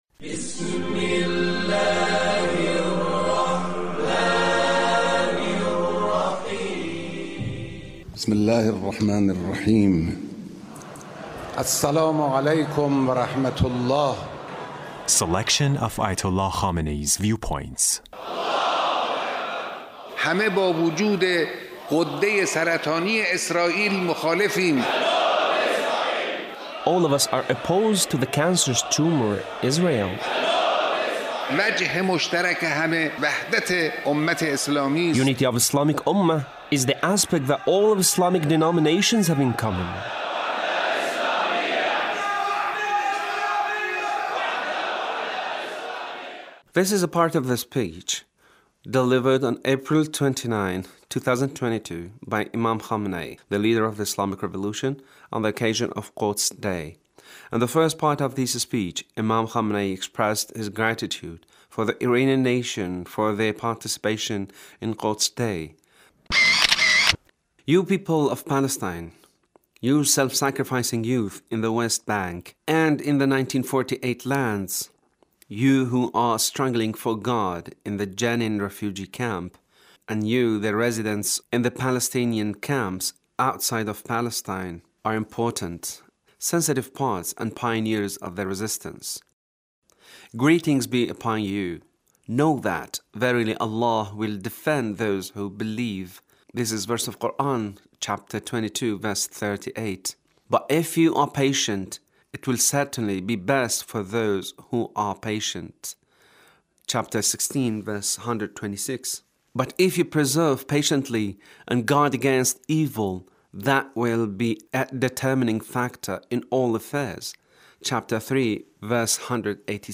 Leader's speech (1404)
The Leader's speech on Quds Day